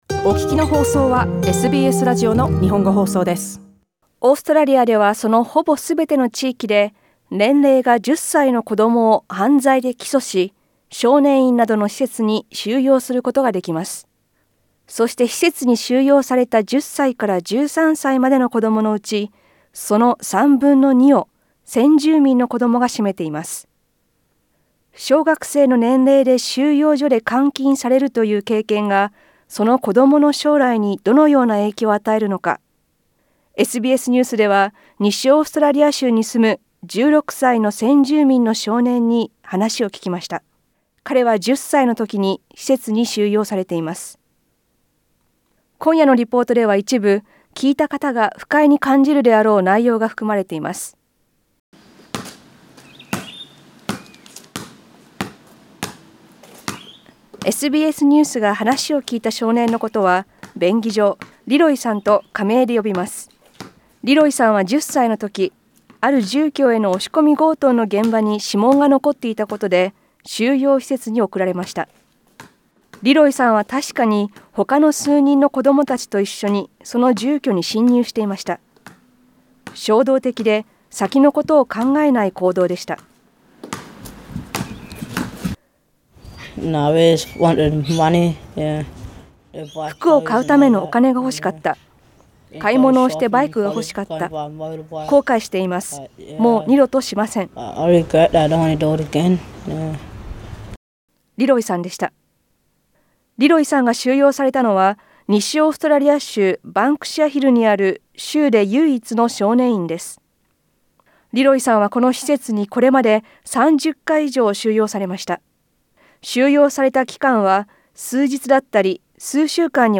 今夜のリポートでは一部、聞いた方が不快に感じるであろう内容が含まれています。